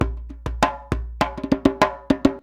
100DJEMB16.wav